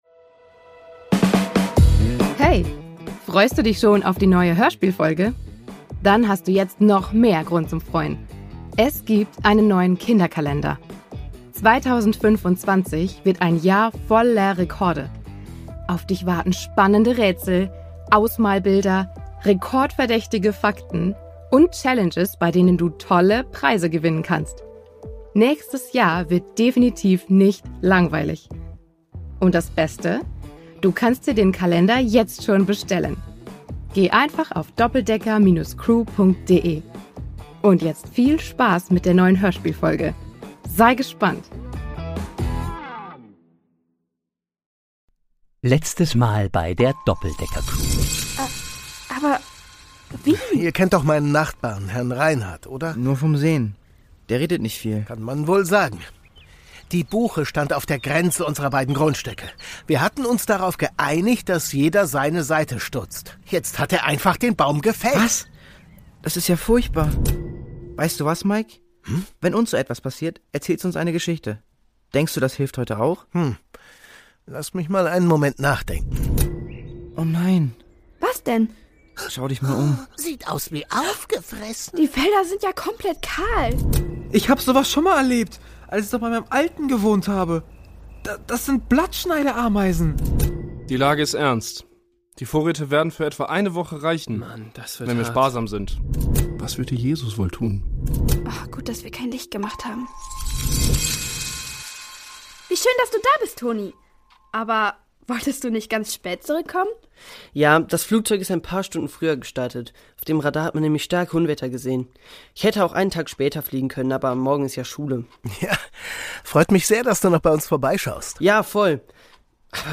Brasilien 8: Farm in Not (2/2) | Die Doppeldecker Crew | Hörspiel für Kinder (Hörbuch) ~ Die Doppeldecker Crew | Hörspiel für Kinder (Hörbuch) Podcast